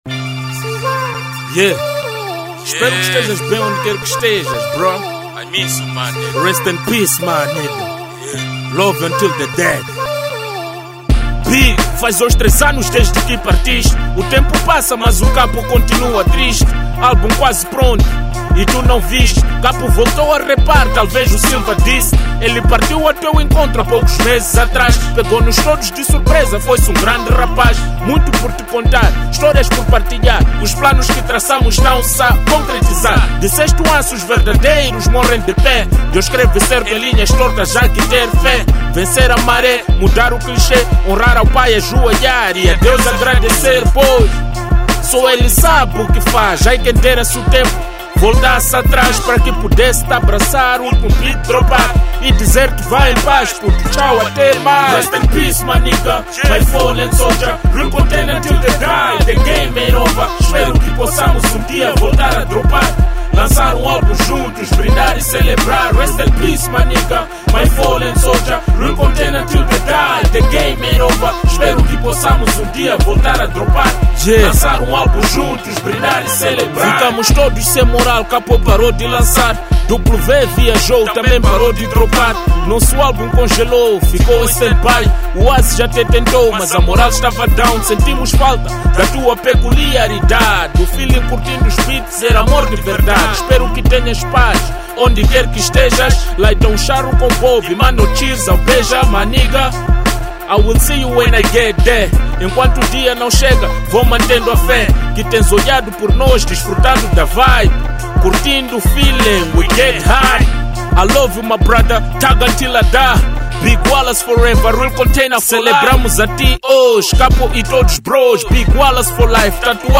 Rap
Hip-Hop/Rap 2023 Mp3 Download
Em uma narrativa emocionante e reflexiva